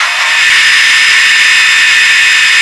rr3-assets/files/.depot/audio/sfx/transmission_whine/tw_onhigh.wav